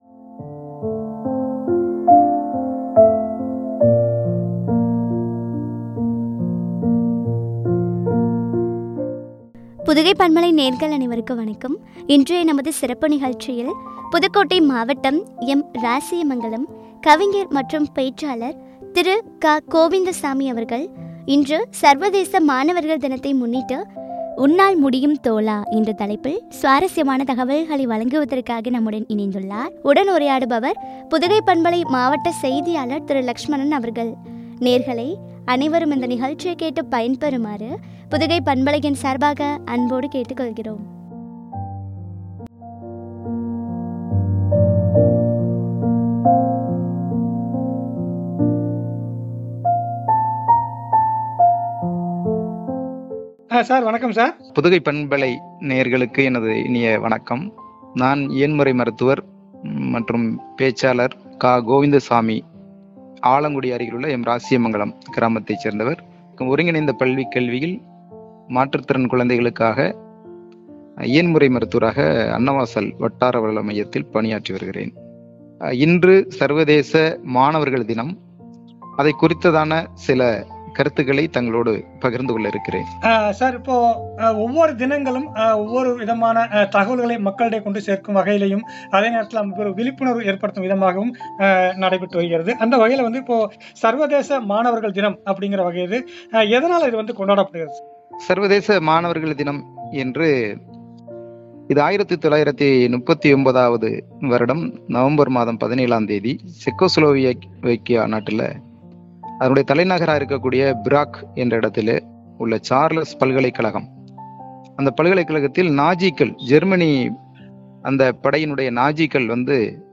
வழங்கிய உரையாடல்.